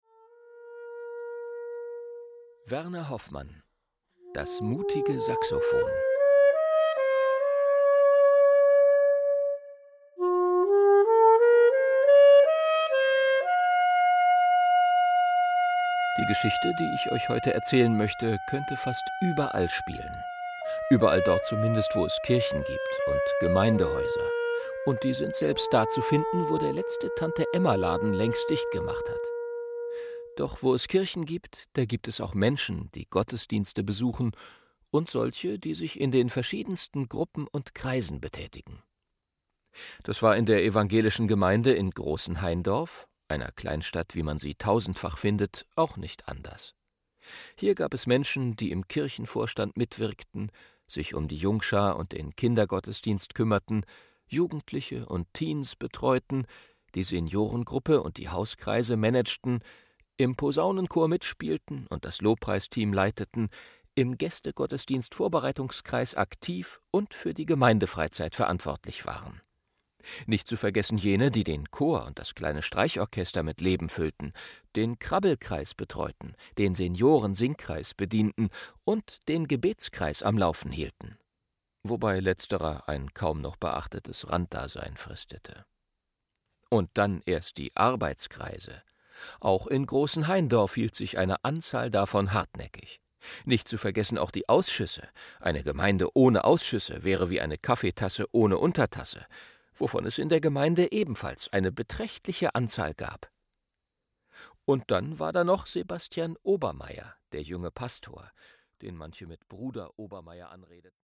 Eine phantastische Musik-Geschichte für alt und (jung) Ein kreatives und witziges Hörbuch mit wertvollen Impulsen für ein ansteckendes musikalisches (Gemeinde-)Leben.
Ein ungewöhnliches Hörbuch, das von einem Profisprecher originell und witzig gelesen wird.